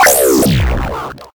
respawn.mp3